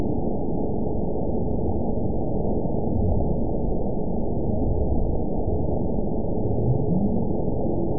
event 917362 date 03/29/23 time 01:15:27 GMT (2 years, 1 month ago) score 9.30 location TSS-AB01 detected by nrw target species NRW annotations +NRW Spectrogram: Frequency (kHz) vs. Time (s) audio not available .wav